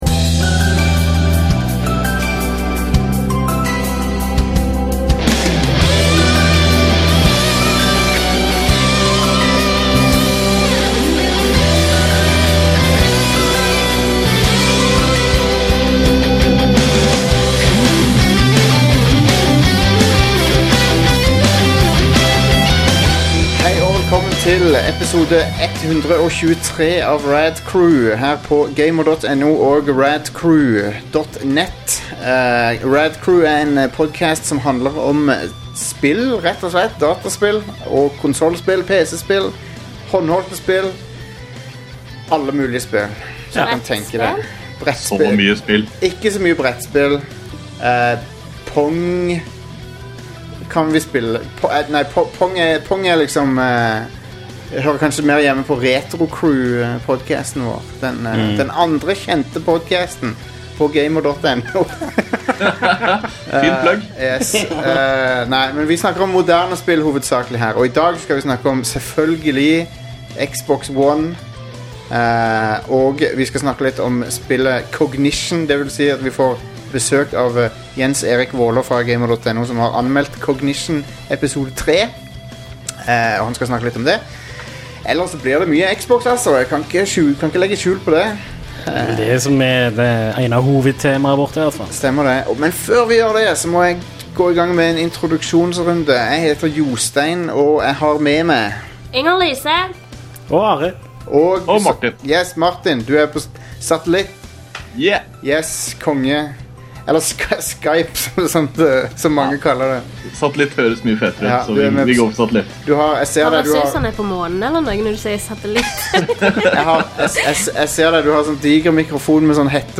Hovedtema denne gangen blir Xbox One, som Microsoft avduket på tirsdag. Gjengen i studio er samlet for å snakke om avsløringene i detalj, helt ned til Don Mattricks alltid like strøkne blazer og hvor mye av konferansen som faktisk gikk med på andre ting enn spill.